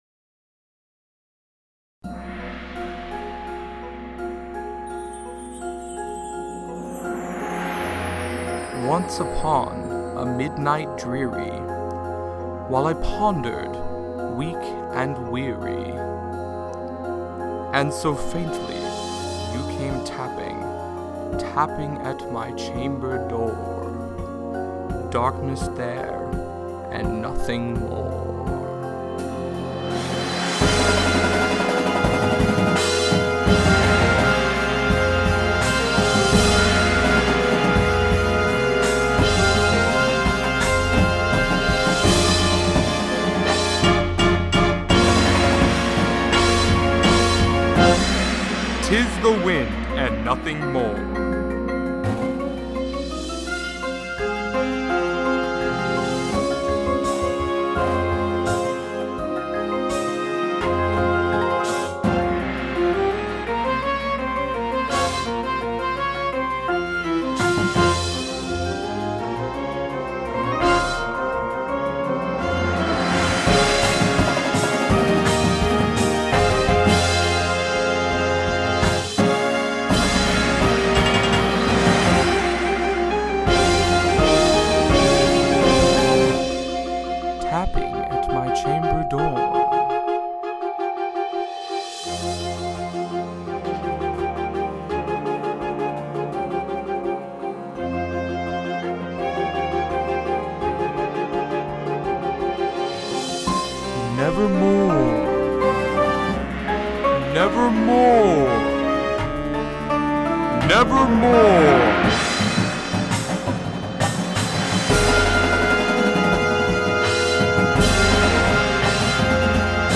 • Tuba
• Snare Drum
• Front Ensemble